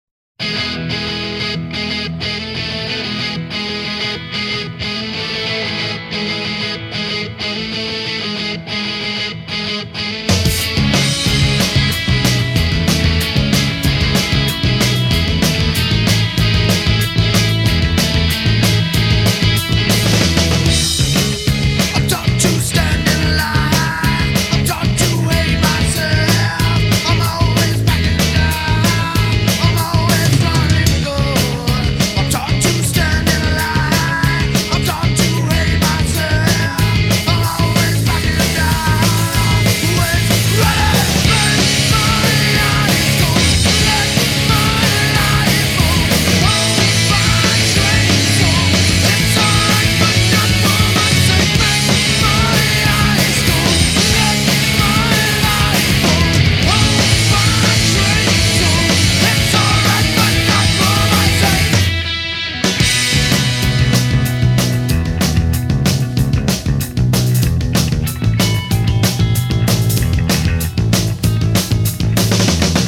GA are a twin guitar quintet with a razor-sharp